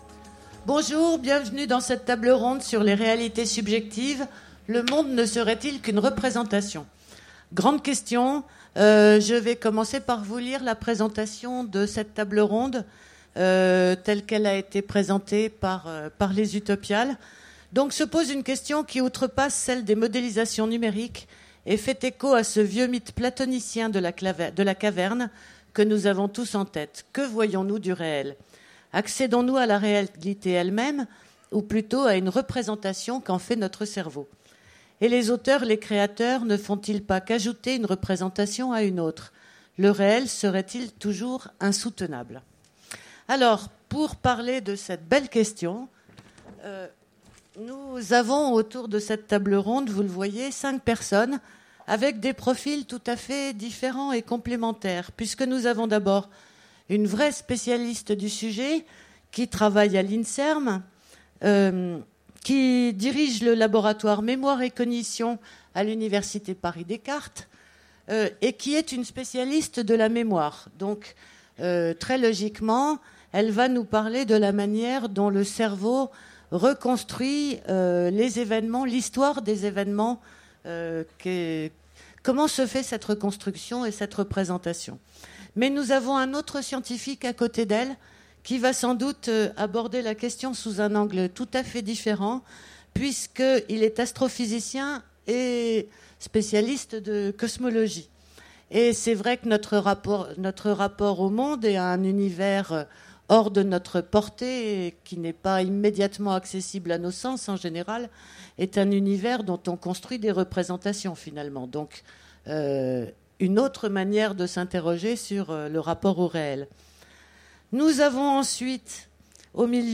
Utopiales 2015 : Conférence Les réalités subjectives
Conférence